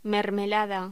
Locución: Mermelada
voz